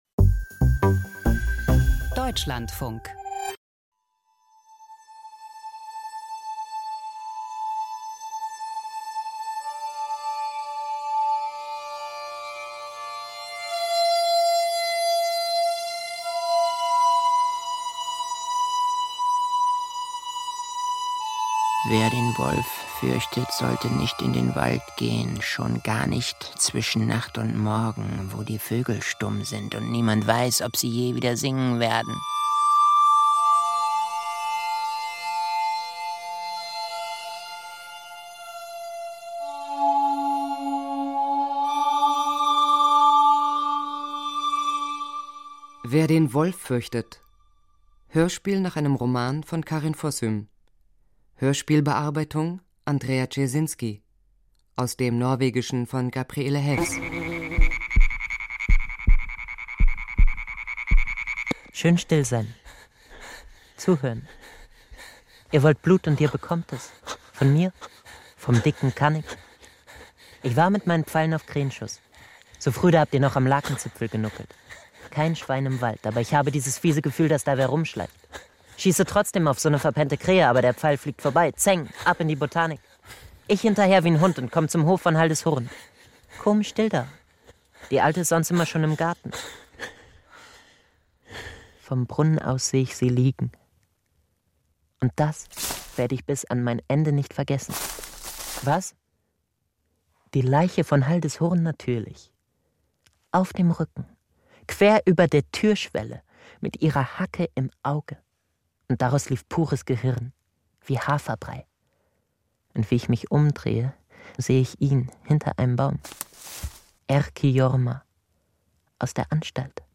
Wer den Wolf fürchtet – Krimi-Hörspiel nach Karin Fossum